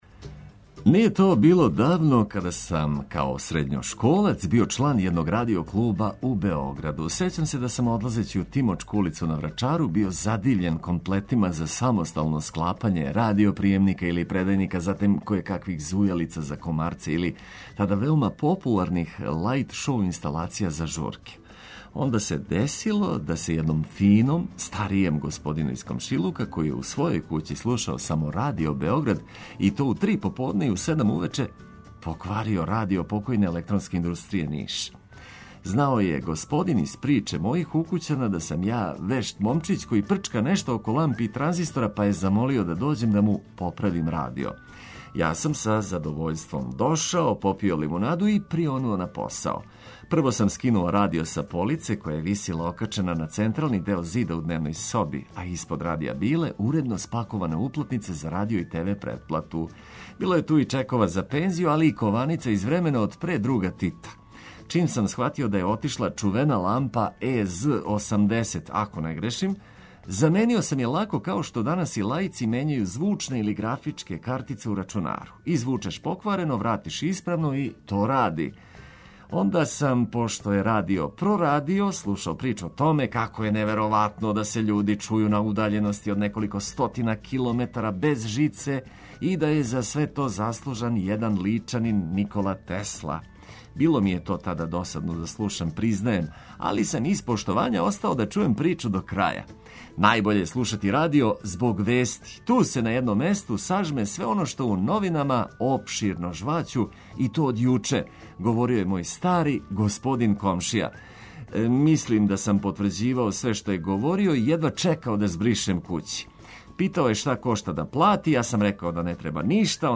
Репортер одлази на Топличин венац где су у току радови на реконструкцији, а из студија одговарамо на питање слушалаца у вези са иницијативом да Куршумлија промени име у Беле Цркве.